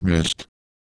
Worms speechbanks
Missed.wav